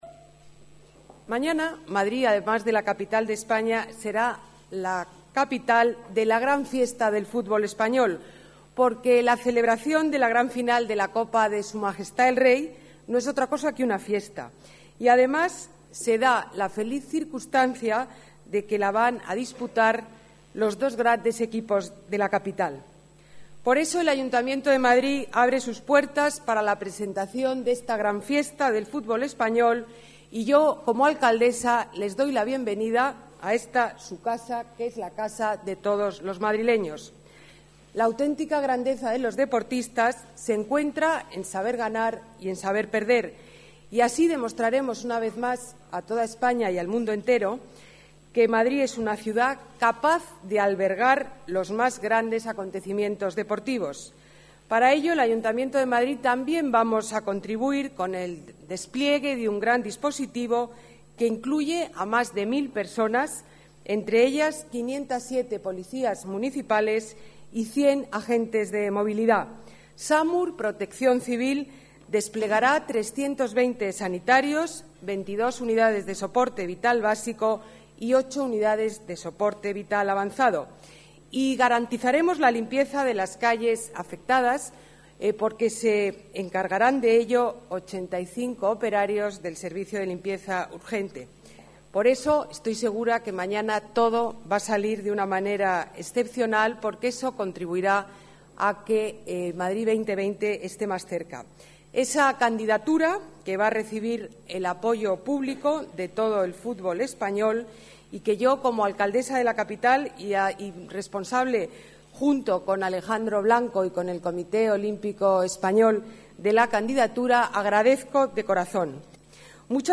Nueva ventana:Declaraciones de la alcaldesa en la recepción a los clubes de fútbol